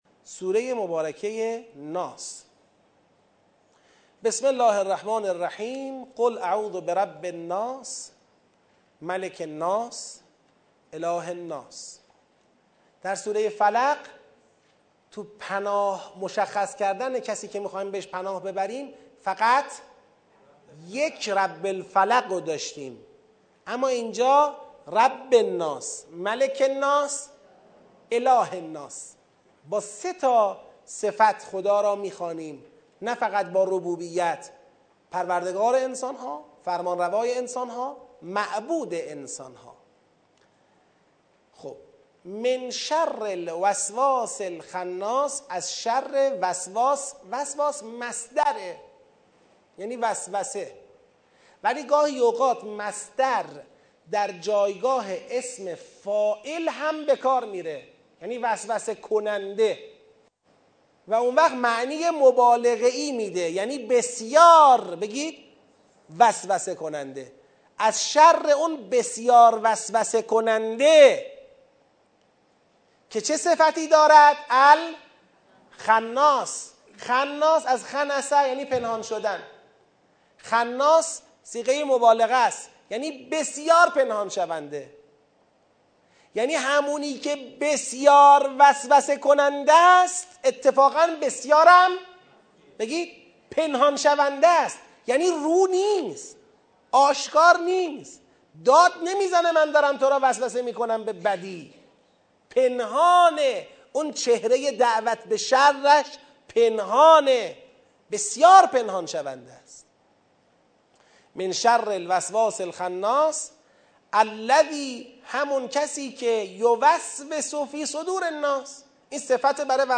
به حول و قوه الهی سطح یک دوره آموزش معرفتی تدبر در قرآن تابستان ۹۸ با حضور ۲۵۰ نفر از قرآن آموزان در مسجد پیامبر اعظم (ص) شهرک شهید محلاتی تهران آغاز شد.